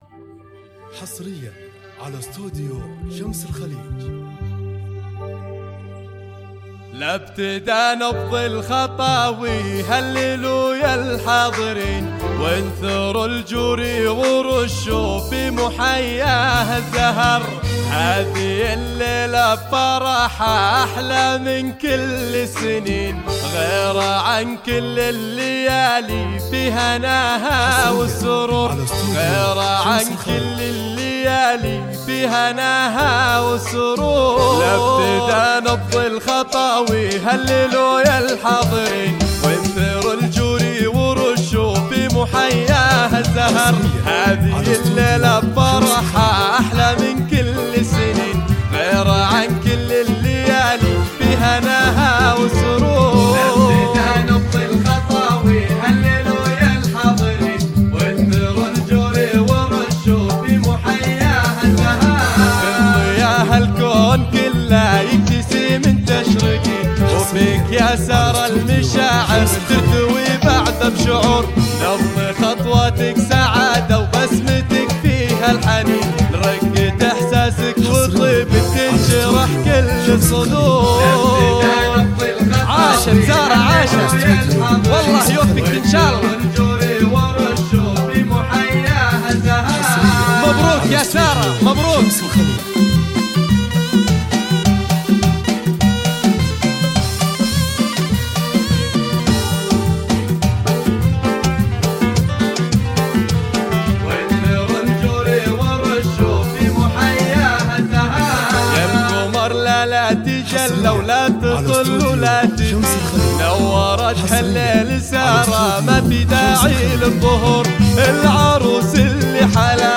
زفات كوشة
زفات موسيقى